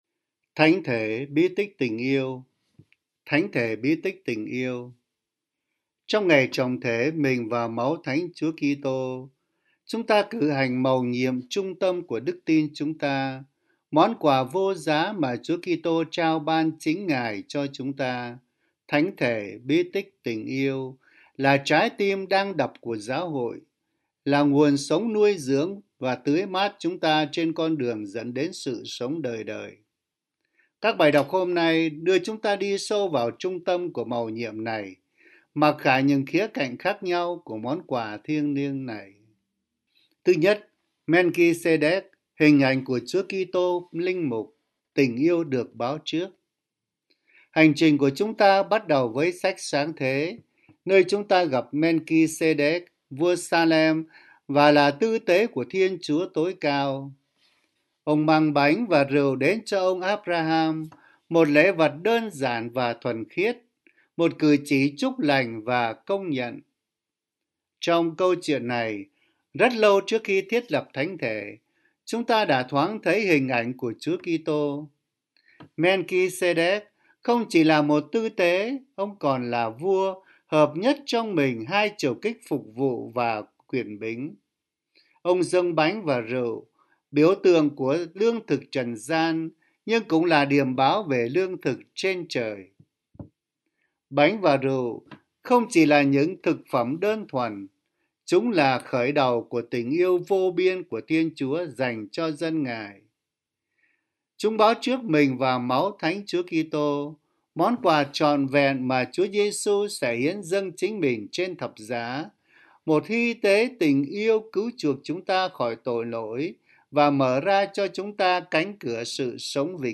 Suy niệm Chúa Nhật